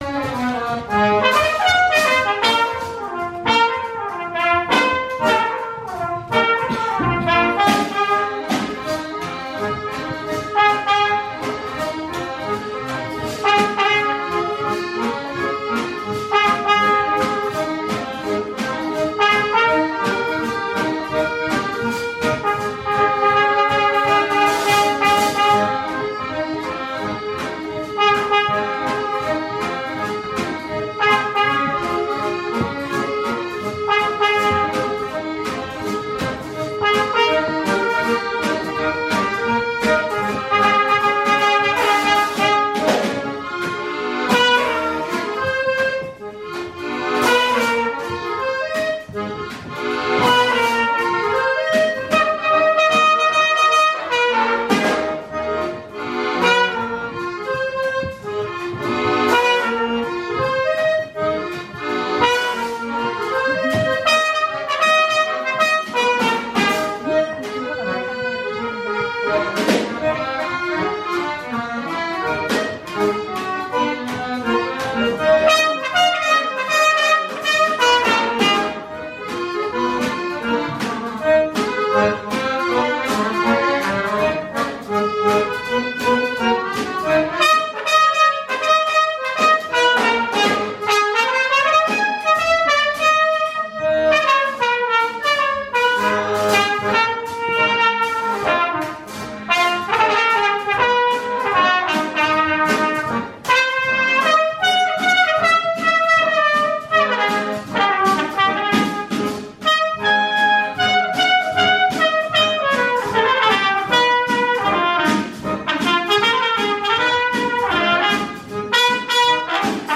יש לי קובץ שמע מתוך הסרטה של מצלמה. אז הוא לא מידי איכותי אבל זה גם משהו
בקובץ שמע יש אקורדיון, איזשהו תוף או משהו וקורנט